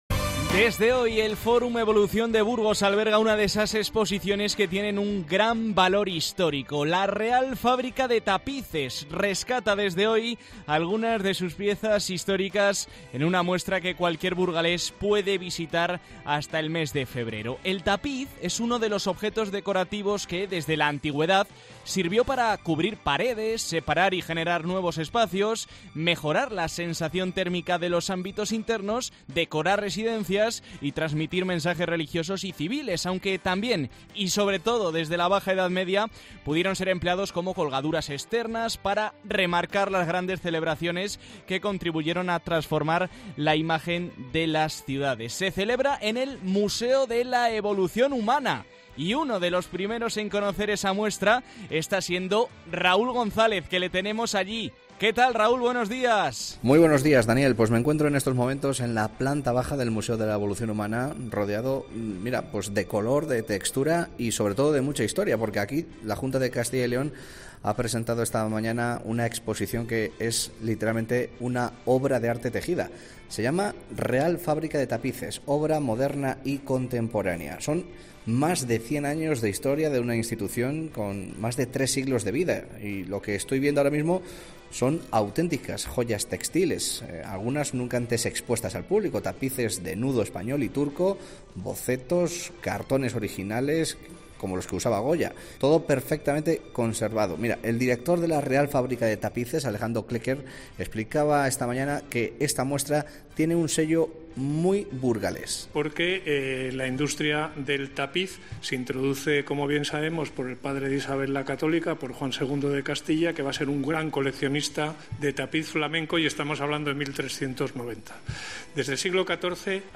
Presentación de la exposición ‘Real Fábrica de Tapices. Obra moderna y contemporánea’